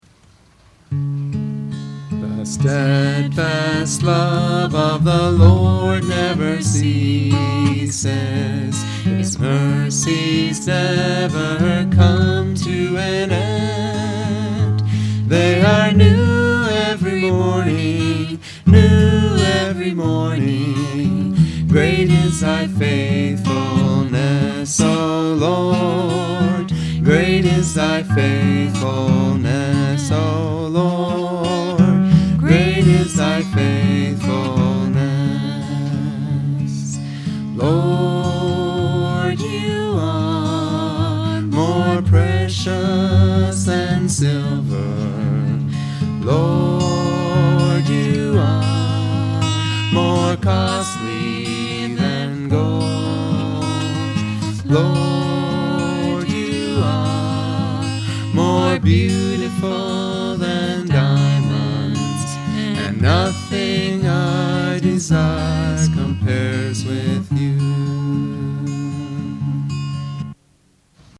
Transpose from D